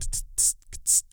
TONGUE HH.wav